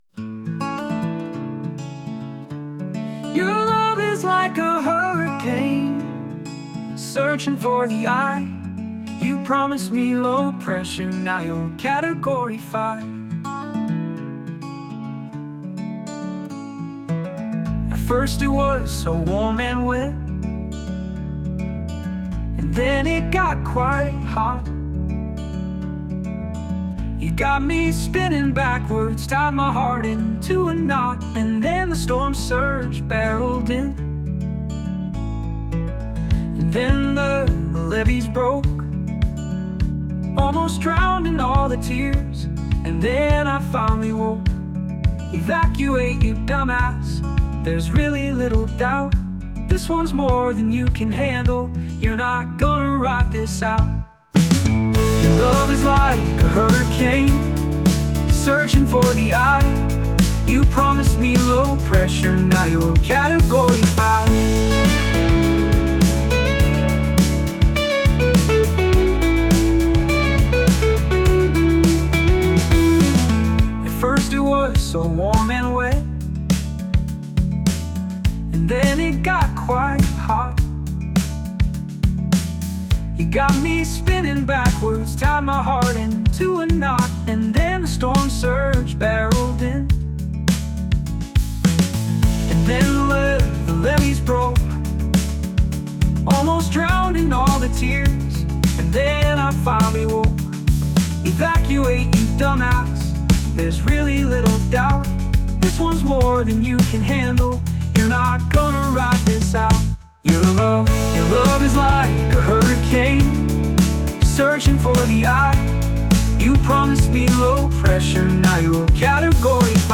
But AI turned it into a hit. I really like the AI version.